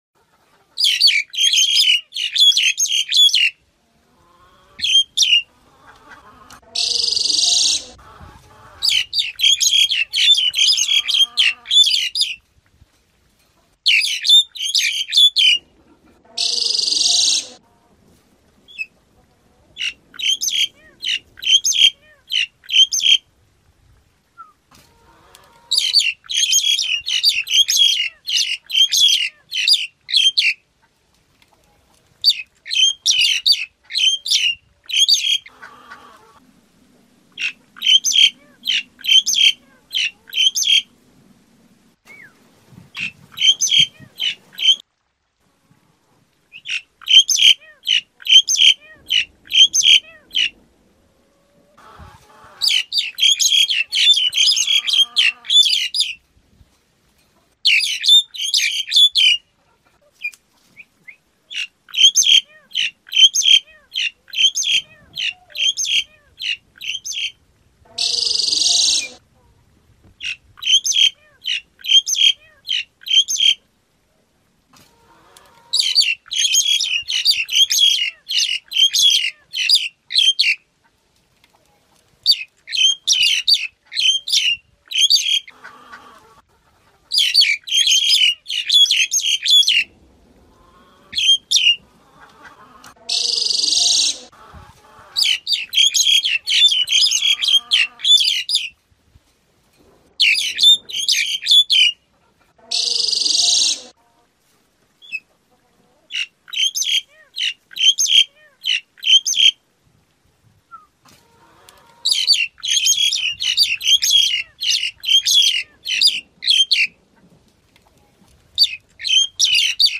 Tiếng Cà Cưỡng hót
Tiếng động vật 533 lượt xem 20/02/2026
Với khả năng bắt chước và biến hóa giọng hót linh hoạt, tiếng Cà Cưỡng mang lại cảm giác vui nhộn, rộn ràng, phản ánh rõ nét hơi thở của thiên nhiên hoang dã và không gian vườn tược yên bình.
• Giọng hót đa sắc thái: Cà Cưỡng nổi tiếng với giọng hót trong trẻo, lúc trầm lúc bổng, đôi khi xen lẫn những tiếng luyến láy bắt chước các loài chim khác.
• Chất lượng thu âm chân thực: File âm thanh được thu âm trực tiếp, giữ trọn vẹn độ đanh và độ vang của tiếng chim trong môi trường tự nhiên, rất dễ dàng để phối trộn (mix) vào các dự án phim ảnh.